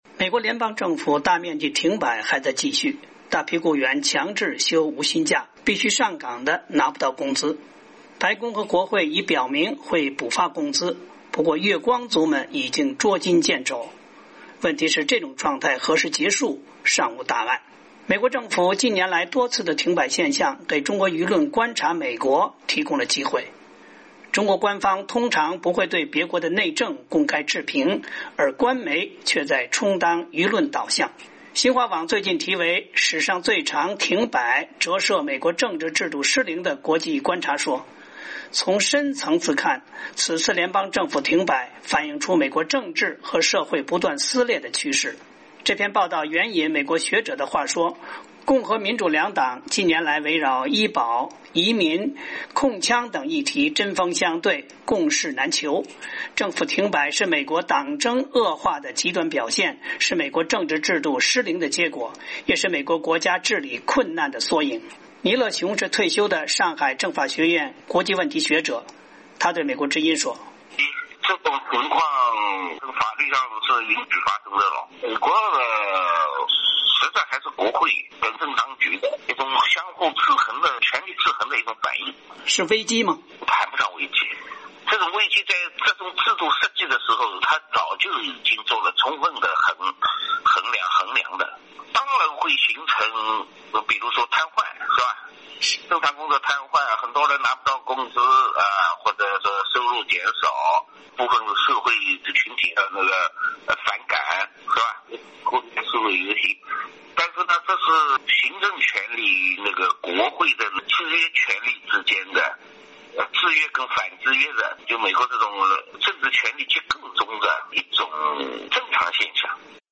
在上海街头，记者星期五和两位人士有一段对话：